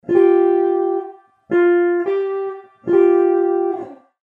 ES2], e l'intervallo fra F e G è un intervallo di seconda naturale, ove per definizione l'intervallo di seconda è una distanza di due semitoni e fra F e G ci sono appunto due semitoni [